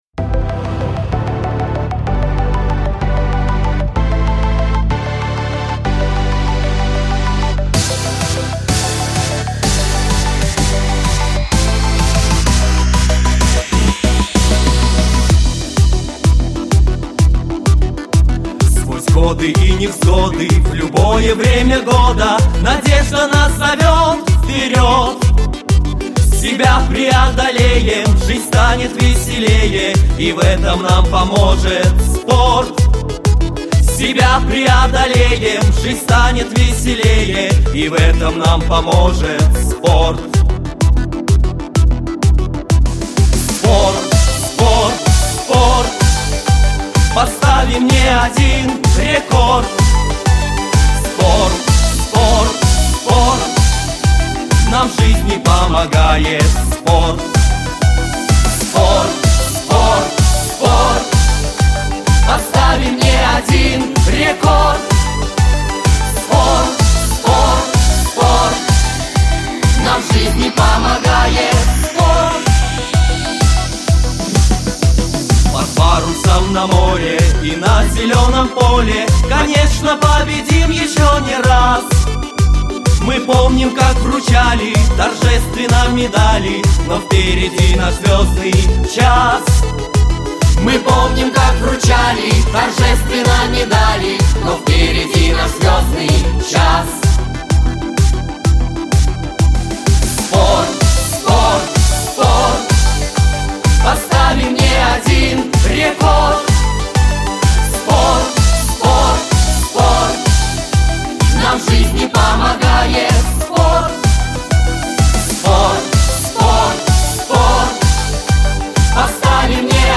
Главная / Песни для детей / Песни про спорт